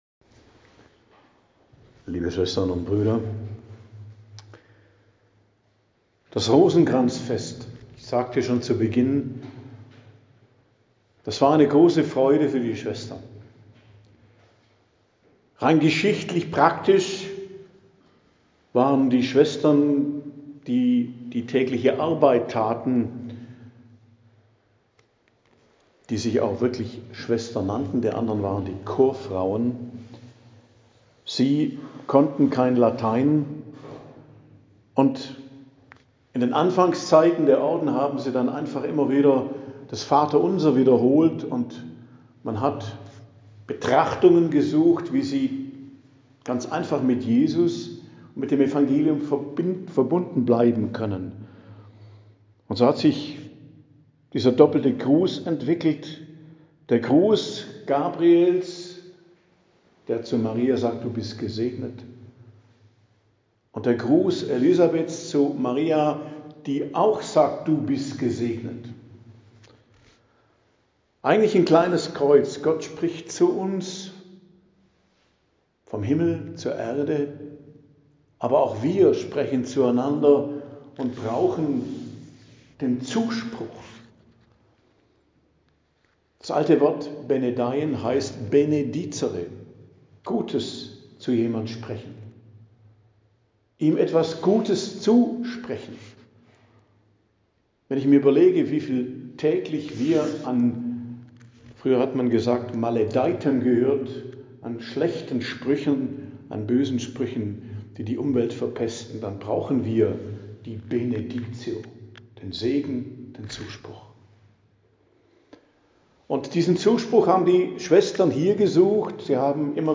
Predigt am Dienstag der 27. Woche i.J., Gedenktag Unserer Lieben Frau vom Rosenkranz, 7.10.2025